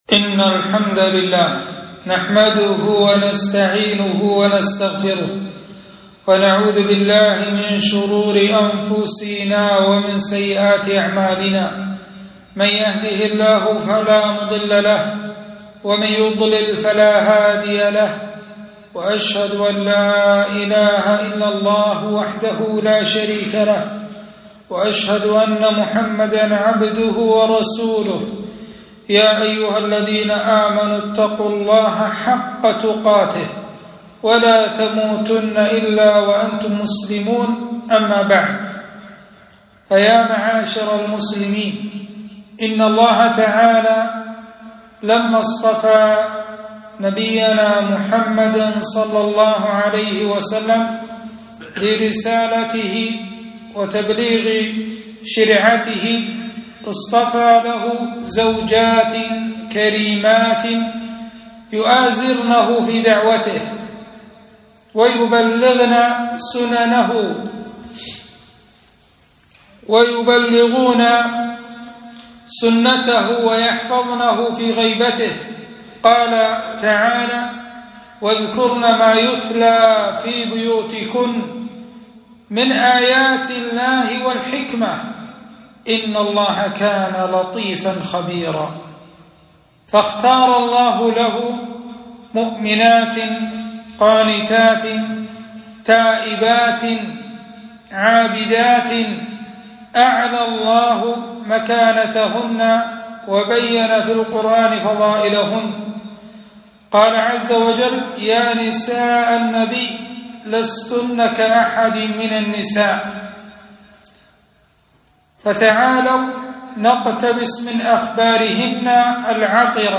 خطبة - أمهات المؤمنين رضي الله عنهن